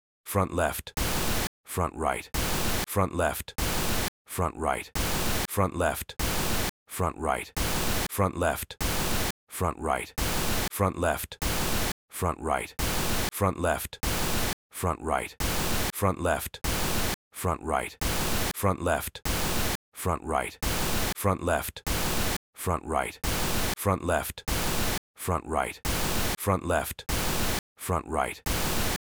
Both 96kHz/24bit stereo and 96kHz/24bit 5.1 channel audio should be played at 96kHz.
stereo_ch-test.flac